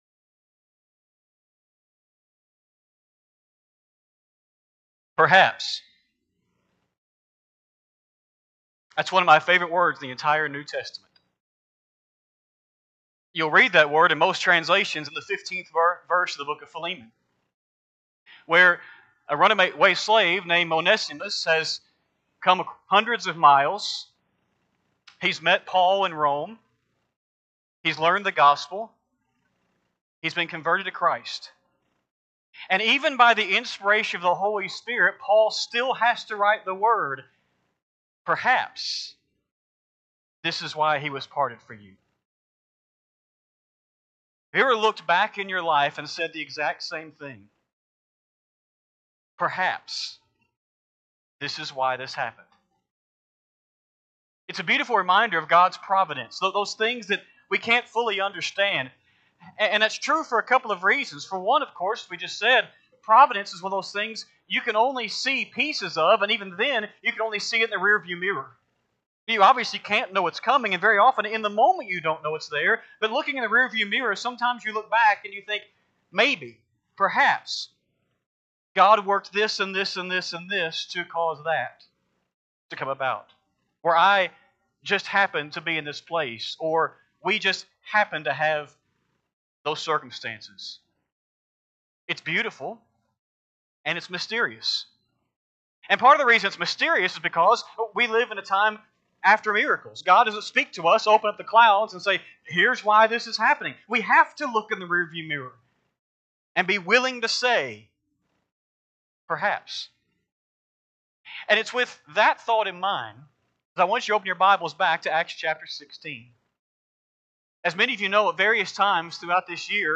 8-3-25-Sunday-AM-Sermon.mp3